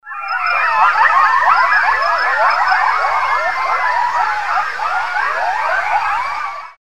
Coyote:
coyotes.wav